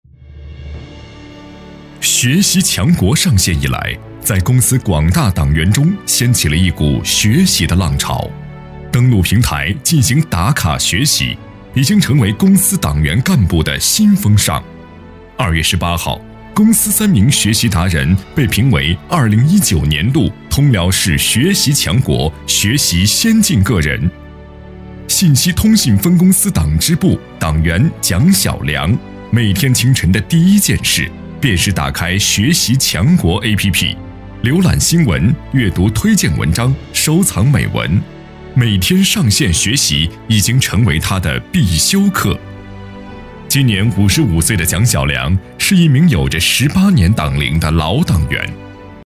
26男-127系列-党政专题
擅长：专题片 广告
特点：大气浑厚 稳重磁性 激情力度 成熟厚重
风格:浑厚配音